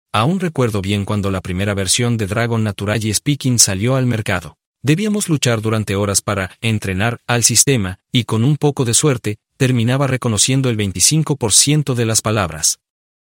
Demo de Speechify